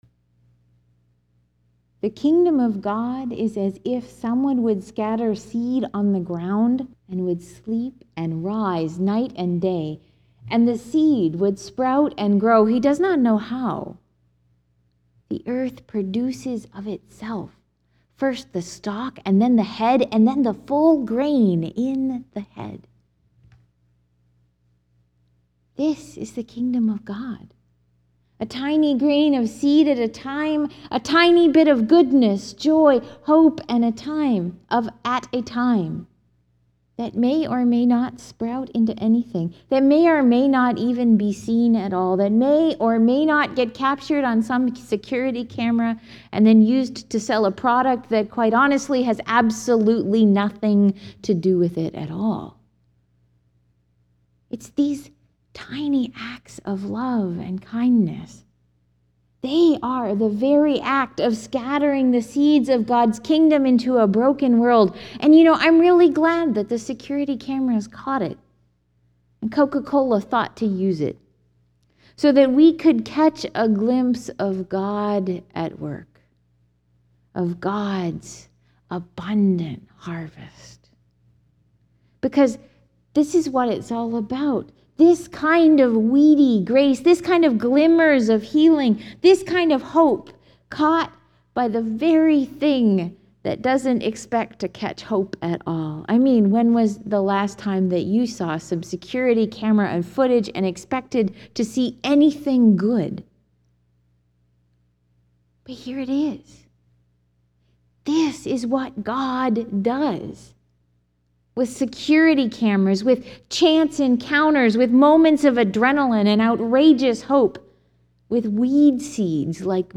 This sermon was started by watching a recent Coca-cola ad as shown on Co.create: “Security Camera Footage in Coca-cola Ad Catches People Being Awesome”